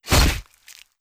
Melee Weapon Attack 5.wav